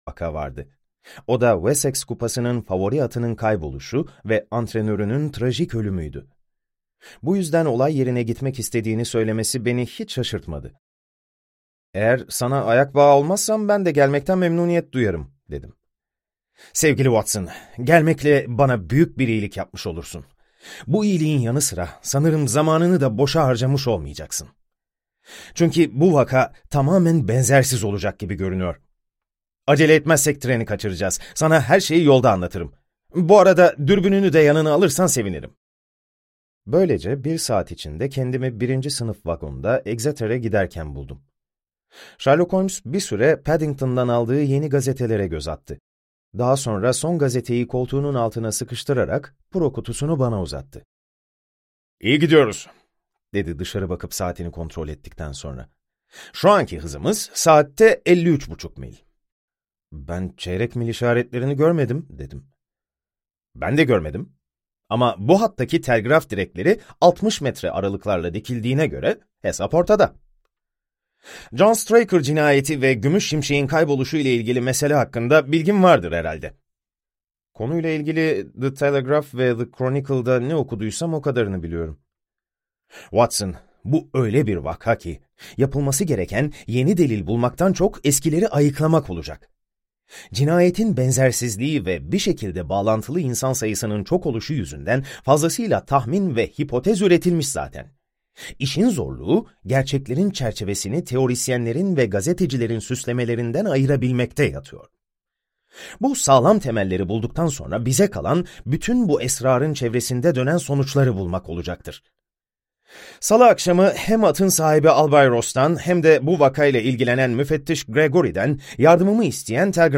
Sherlock Holmes - Suç Detayda Saklıdır - Seslenen Kitap